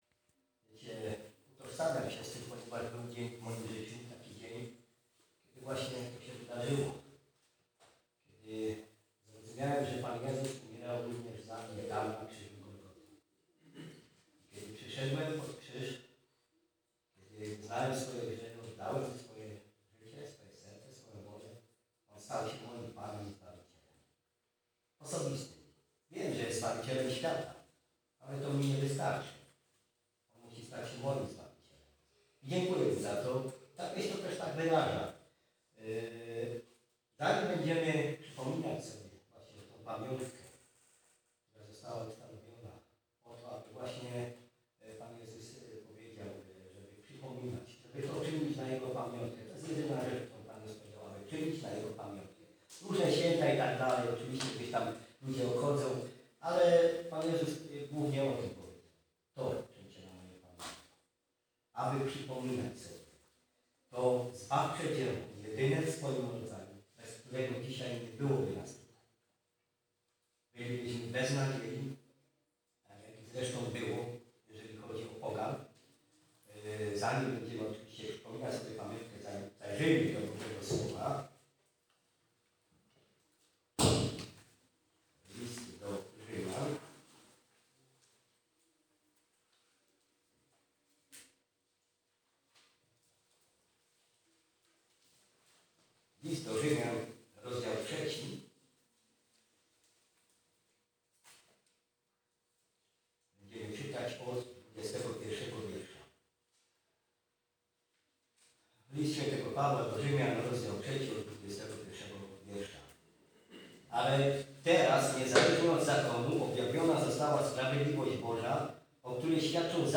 nabożeństwo